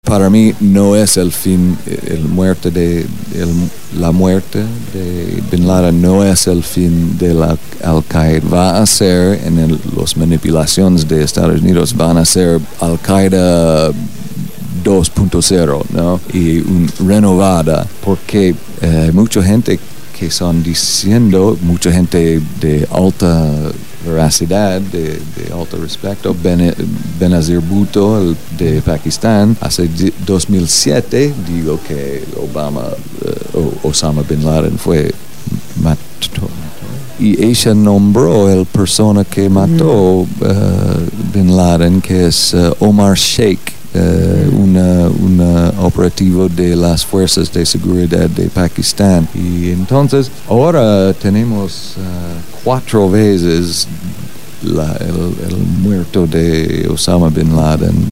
en los estudios de Radio Gráfica FM 89.3